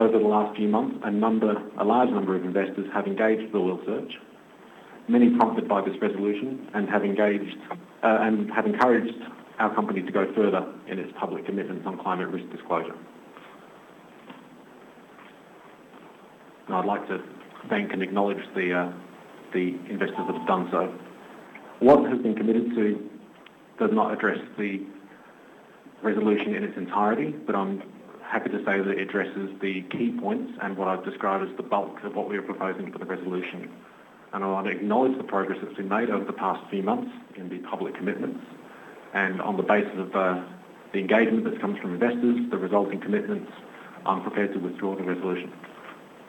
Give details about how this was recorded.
addressed the AGM and withdrew the resolution on the basis of the progress that had been made, making clear that investors need to know whether the companies they are invested in will be compatible with a decarbonised economy.